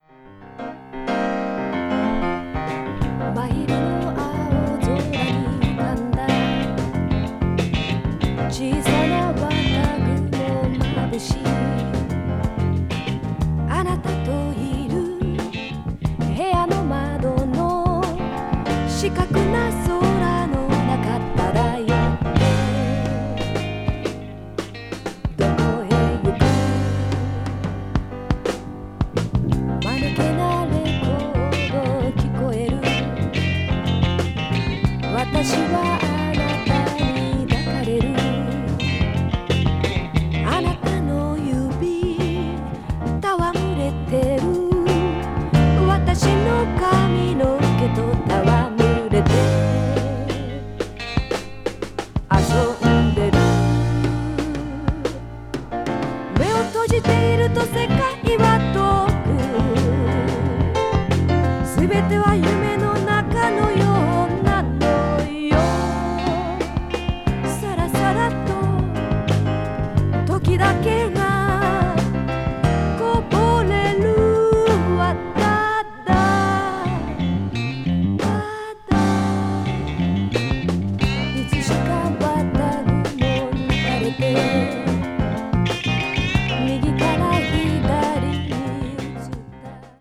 media : EX/EX(わずかにチリノイズが入る箇所あり)
blues rock   classic rock   funky rock   rare groove